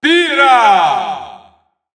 Announcer pronouncing Pyra's name in Italian.
Category:Pyra (SSBU) Category:Announcer calls (SSBU) You cannot overwrite this file.
Pyra_Italian_Announcer_SSBU.wav